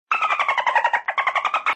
Ringetone Stemme Delfin
Kategori Dyr